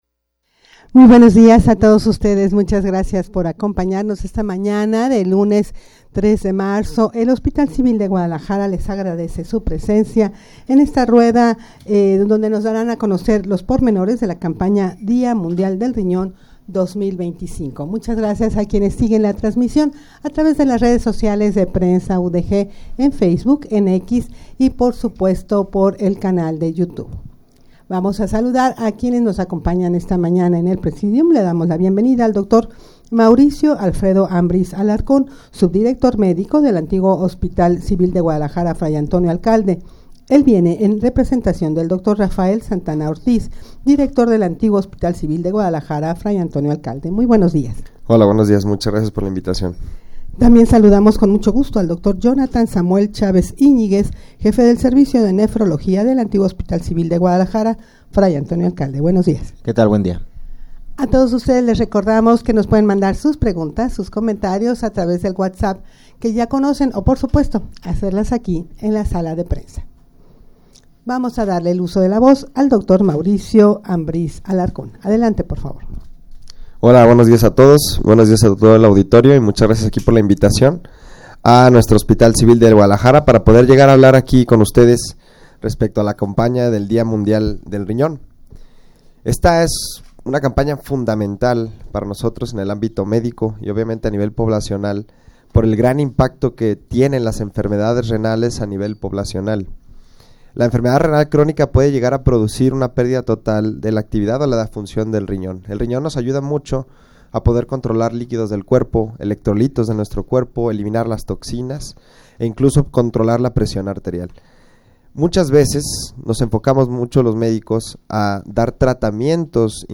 Audio de la Rueda de Prensa
rueda-de-prensa-campana-dia-mundial-del-rinon-2025-que-este-ano-tiene-como-lema-tus-rinones-estan-bien.mp3